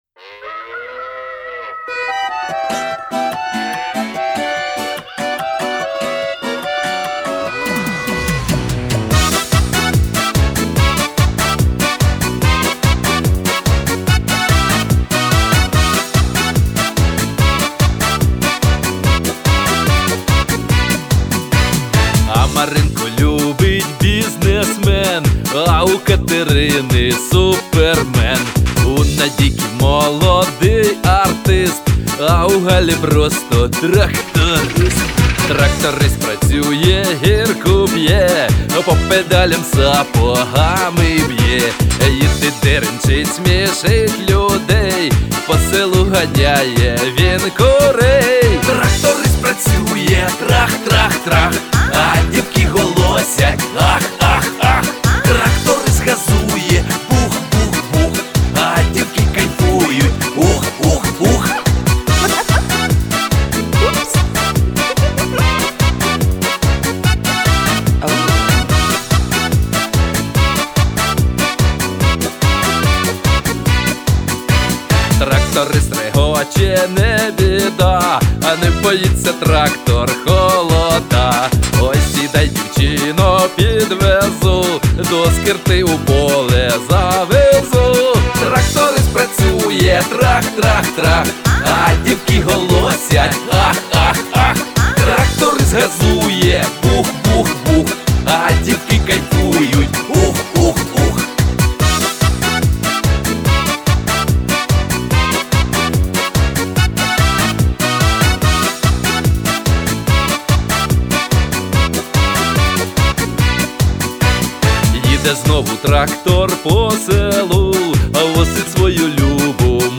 щоб насолодитися її веселим і легким звучанням.